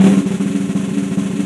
SI2 SNARER0I.wav